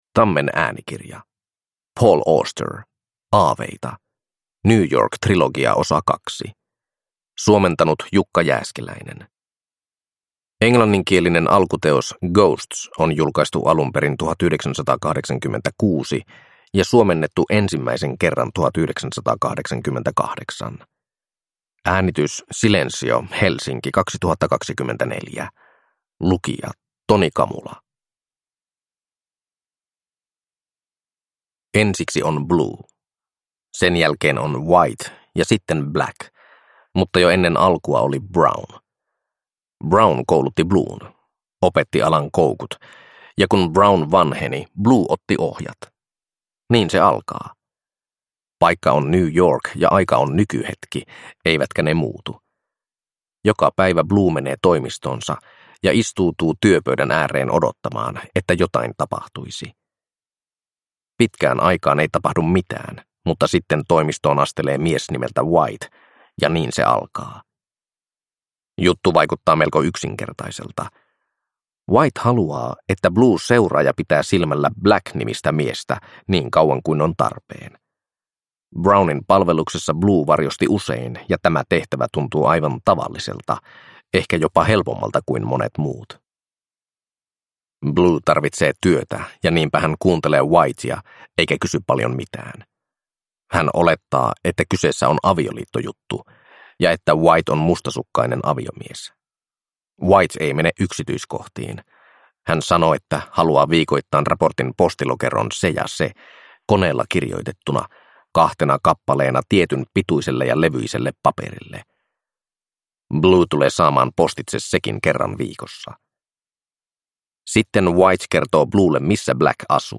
Aaveita – Ljudbok